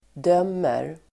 Uttal: [d'öm:er]